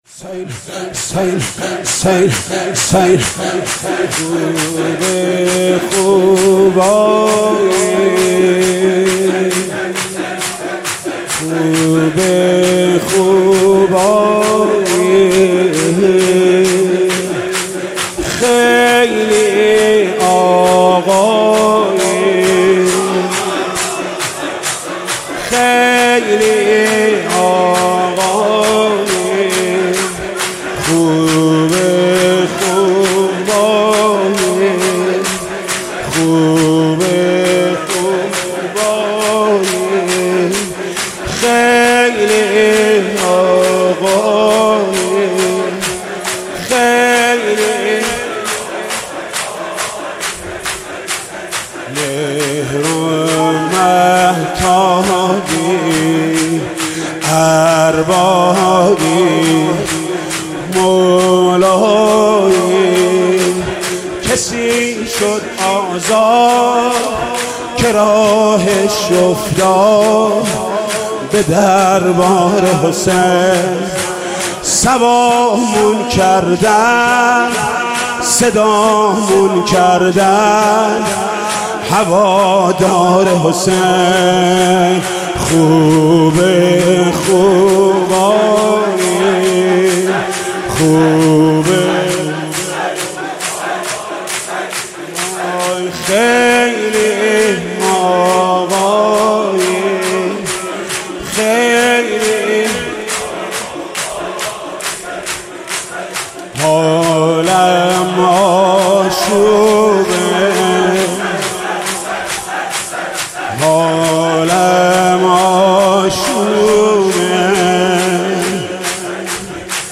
صوت/مداحی محمود کریمی/شب هفتم محرم